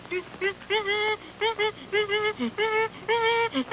Whining Monkey Sound Effect
Download a high-quality whining monkey sound effect.
whining-monkey.mp3